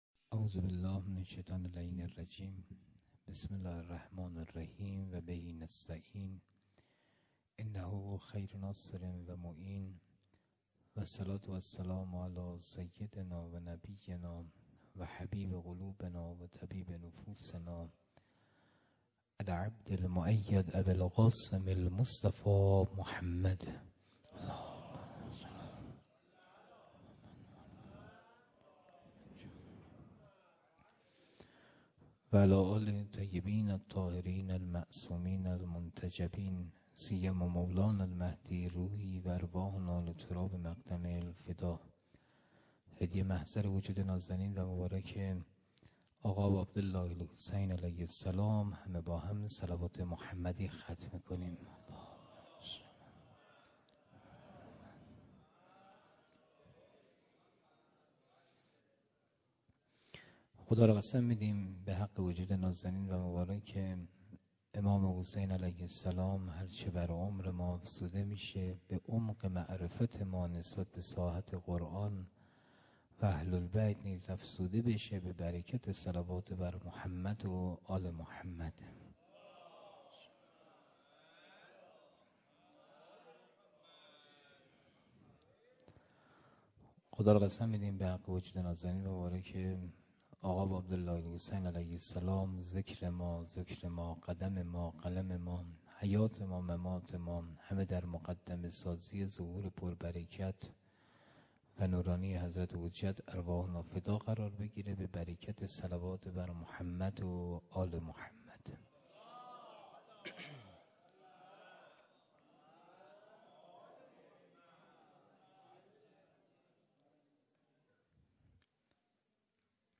تفسیر سوره فصلت - محرم 1395 - هیئت حضرت علی اصغر(ع)